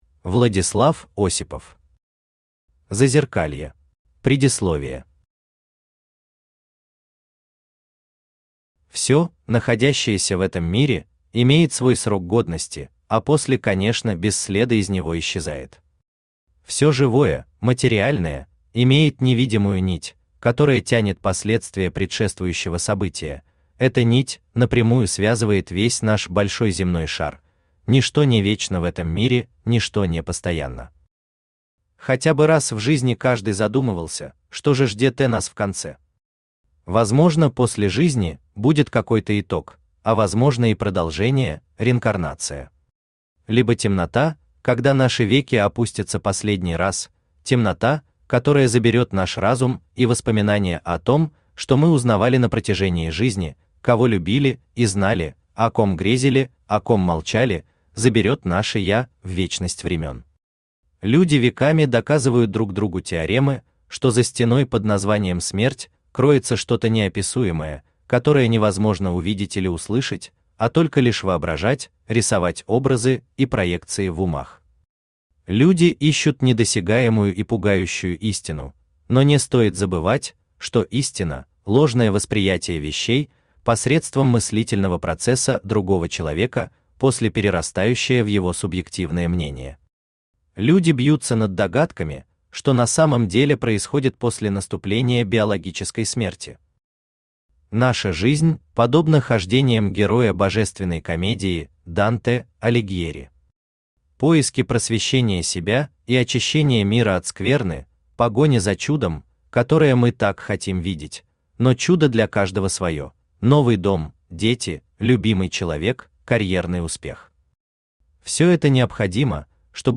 Аудиокнига Зазеркалье | Библиотека аудиокниг
Aудиокнига Зазеркалье Автор Владислав Дмитриевич Осипов Читает аудиокнигу Авточтец ЛитРес.